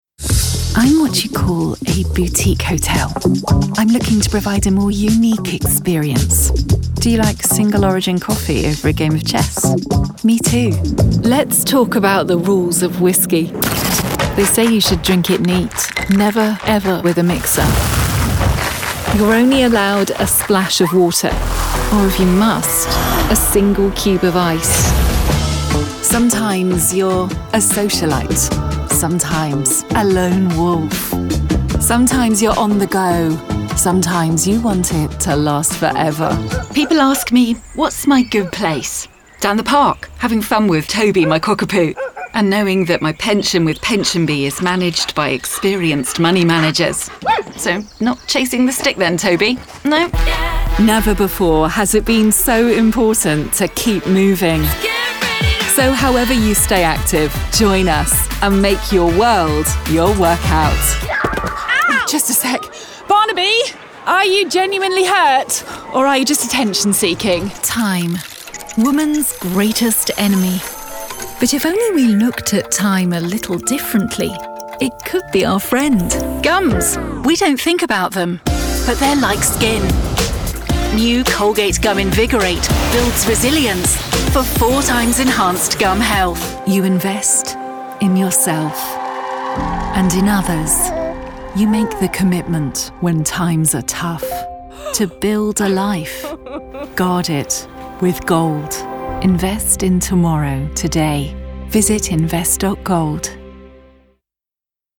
English (Neutral - Mid Trans Atlantic)
Adult (30-50) | Older Sound (50+)
Authentic and native British speakers bring a professional, rich, refined and smooth sound to your next voice project.